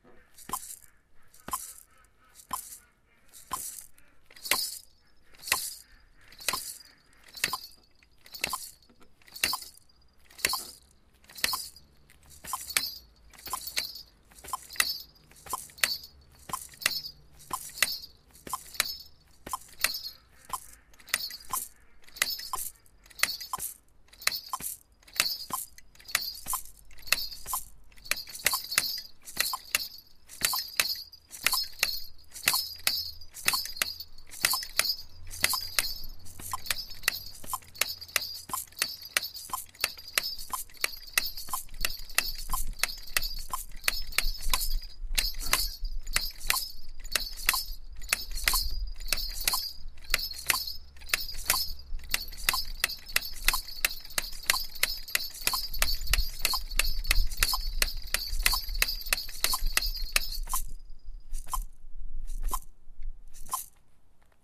Am Anfang sind vier Einzähler vom Metronom zu hören, was die ganze Zeit durchläuft. In einer Minute 40 Takte.
Da drei statt vier Schläge ab Buchstabe Q auf einen Takt kommen, wirkt das Tempo langsamer.